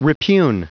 Prononciation du mot repugn en anglais (fichier audio)
Prononciation du mot : repugn